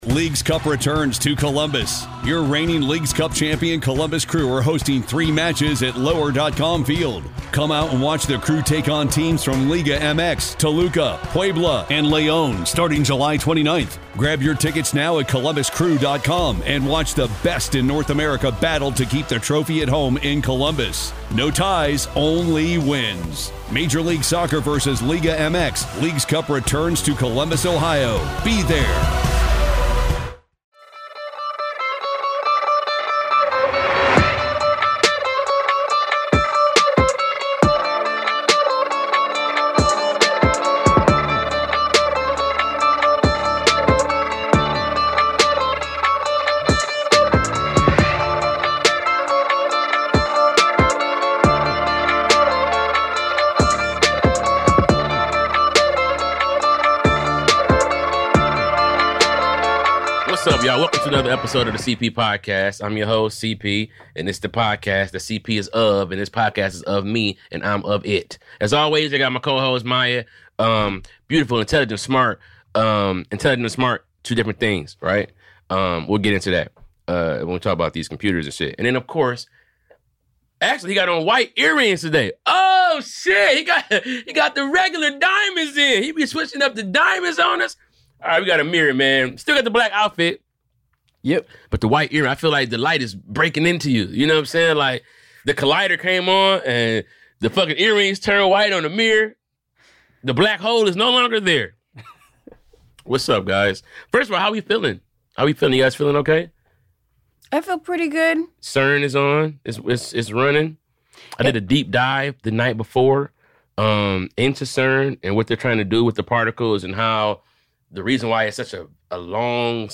Debate Episode!